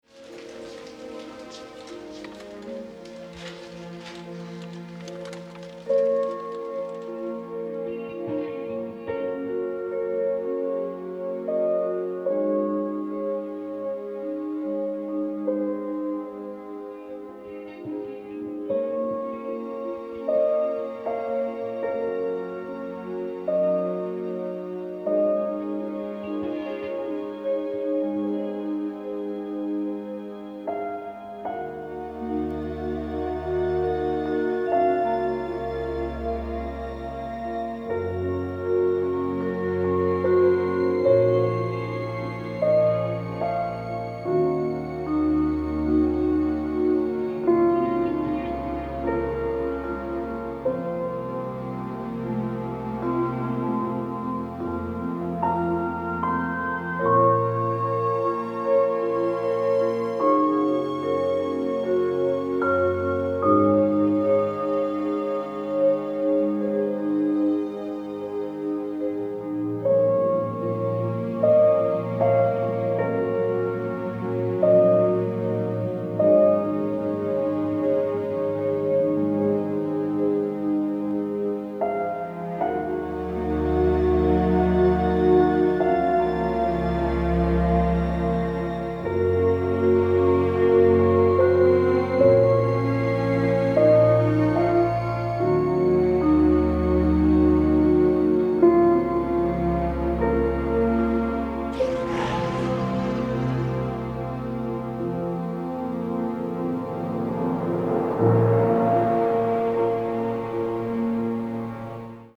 Original Score